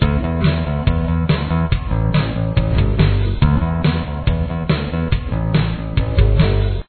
For the verse, only the bass guitar plays.
Bass